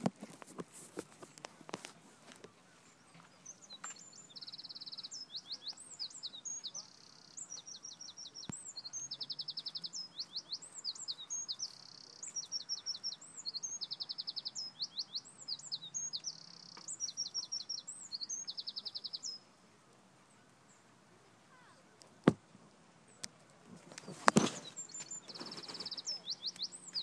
Wren song